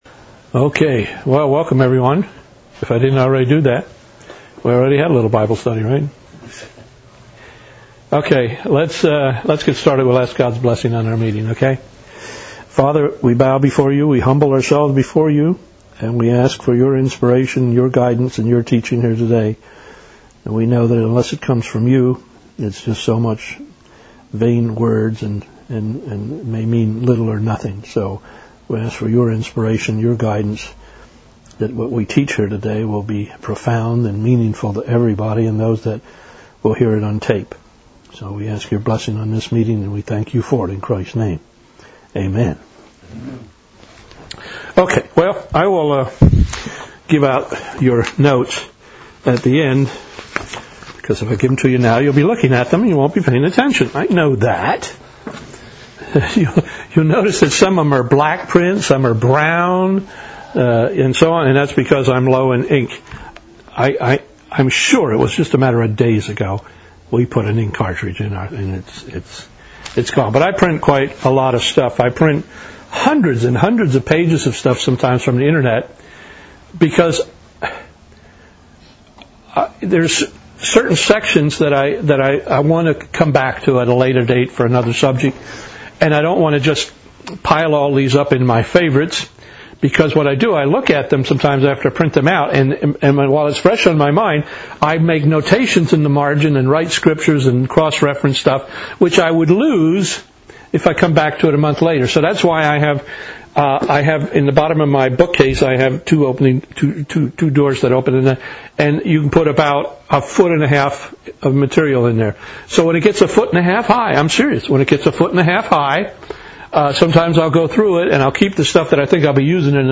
February 4, 2007 first half bible study on "marriage."